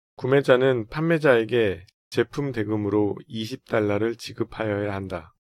구어체 한국어(성인 남성): 구매자는 판매자에게 제품 대금으로 20USD를 지급하여야 한다.